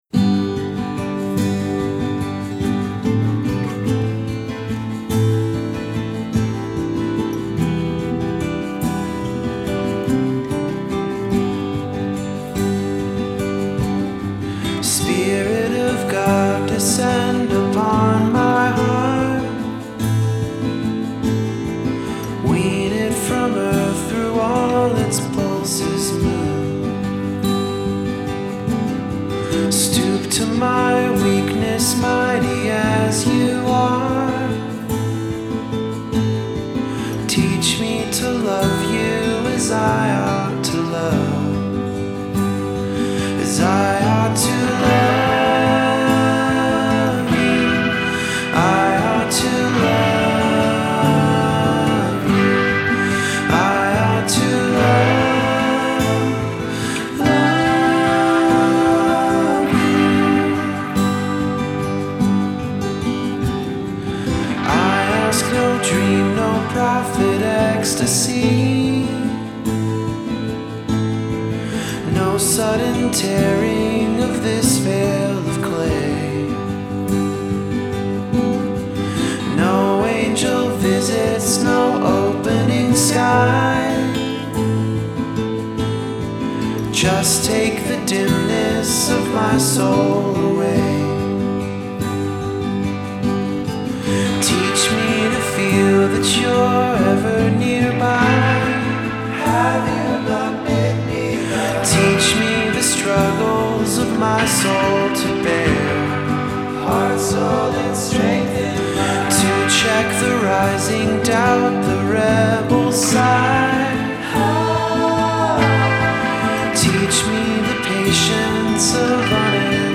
Genre: Alternative, Indie Rock